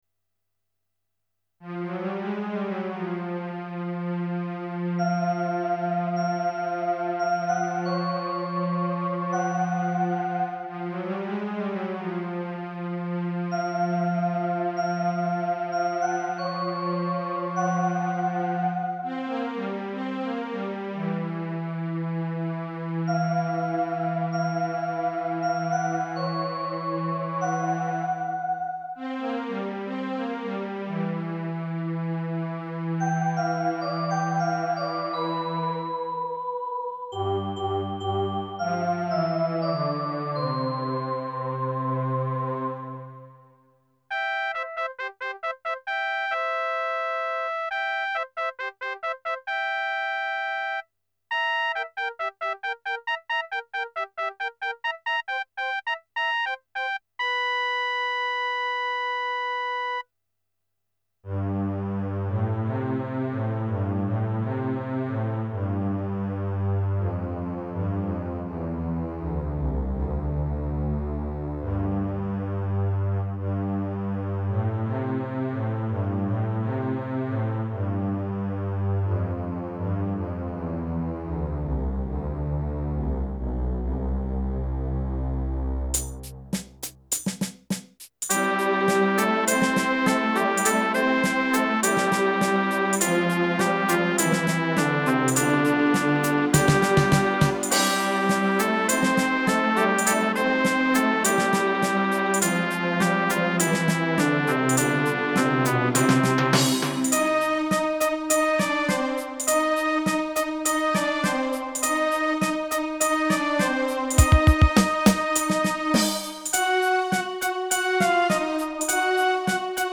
Tuneful and catchy.